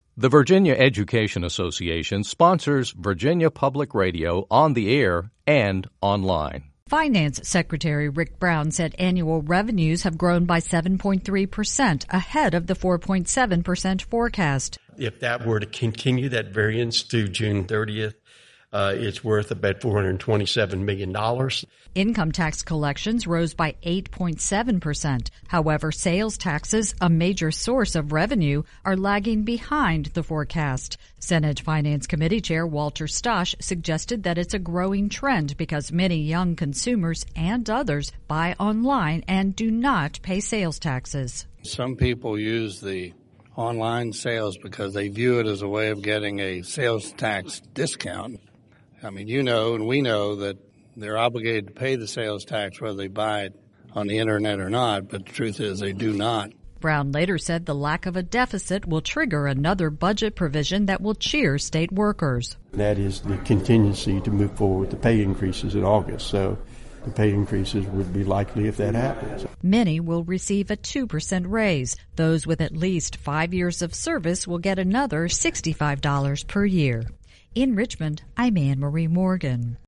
Finance Secretary Ric Brown addresses the Senate Finance CommitteeAfter facing a $439-million shortfall at the end of the last state fiscal year, the Commonwealth is poised to reap the benefits of an improving economy with a multi-million-dollar budget surplus.